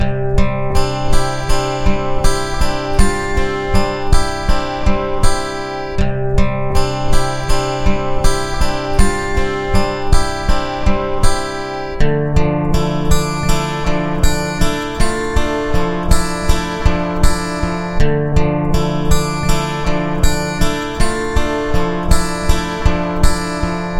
钢琴节拍旋律循环
描述：钢琴节拍旋律循环用钢琴，pad和声码器制作。
标签： pad 流行摇滚 电影 循环 pop 音乐 幻想 视频 节奏 钢琴 groove 声码器 剪辑 低音声线 节拍 80
声道立体声